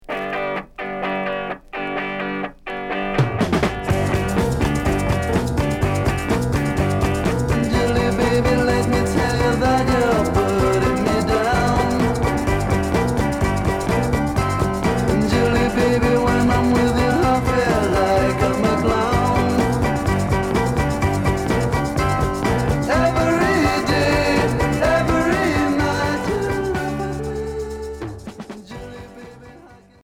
Pop rock